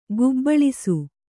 ♪ gubbaḷisu